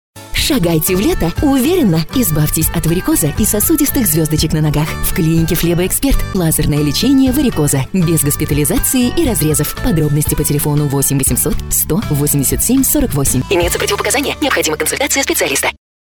Началось размещение рекламы на радиостанции "Радио Дача" компании "Флебоэксперт" в г. Пятигорске.